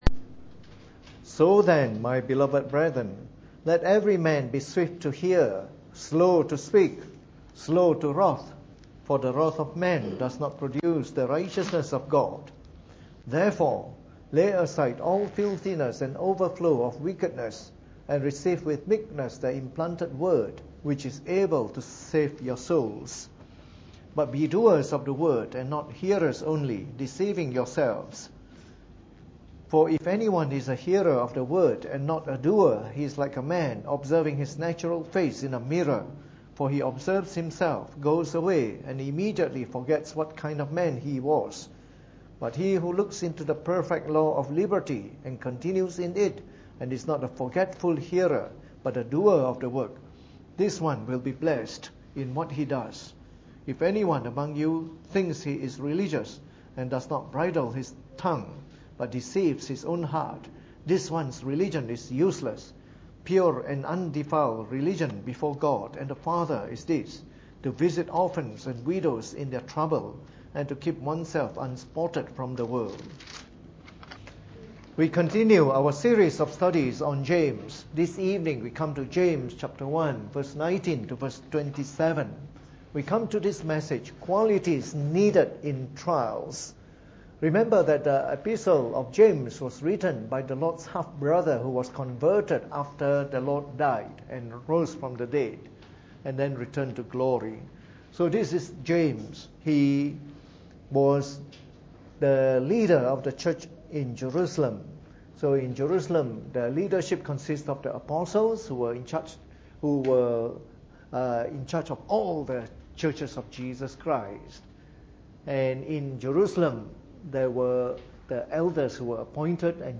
Preached on the 14th of October 2015 during the Bible Study, from our new series on the Epistle of James.